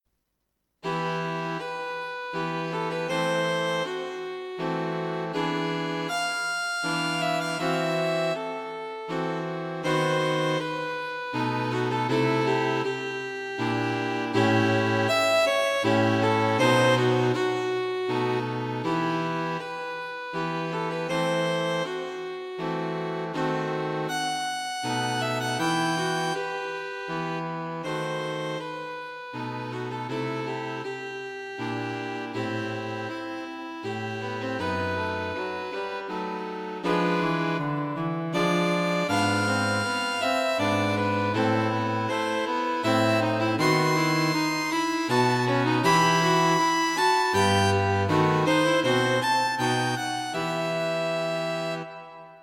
String Quartet for Concert performance
A gentle piece in A minor.